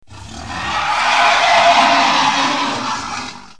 Dino Roar 1
Category: Sound FX   Right: Personal
Tags: monster movie godzilla clover alien